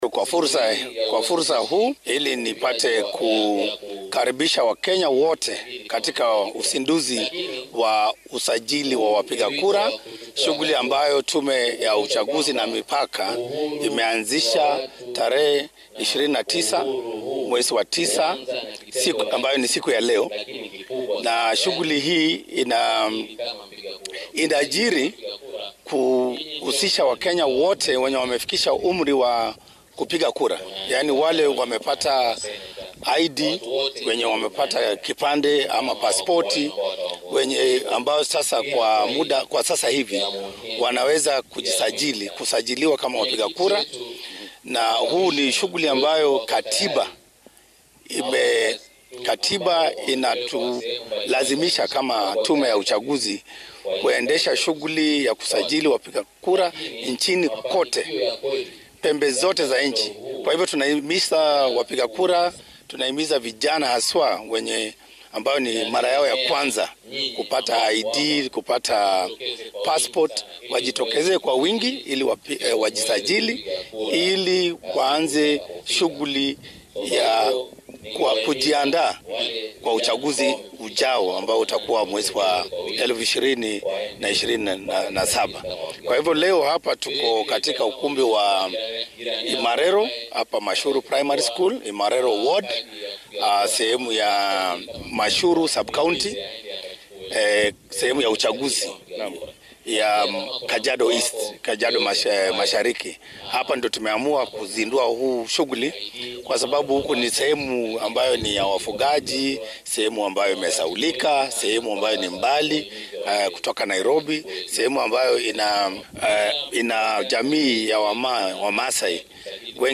Mid ka mid ah hawlwadeenada IEBC ayaa ka warbixinaya Barnaamijkan maanta laga daahfuray Kajiado.